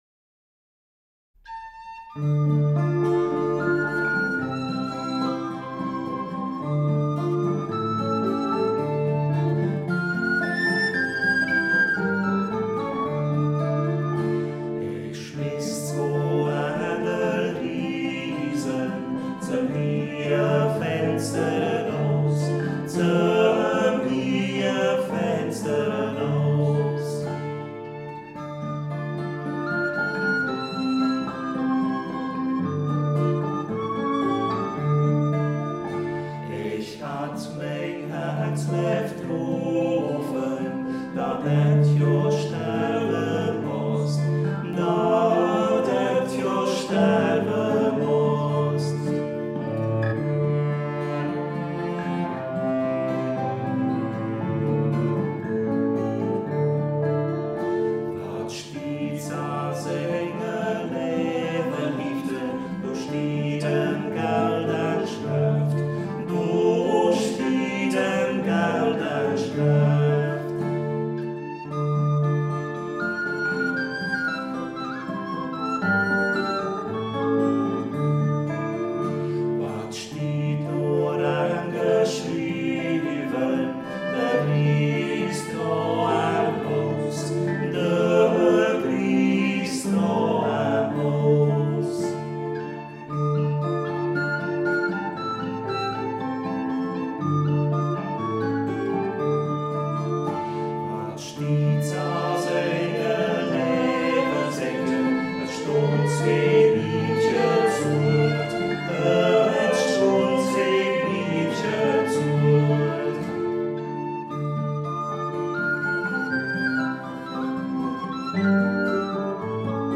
Volkslied
Umgangss�chsisch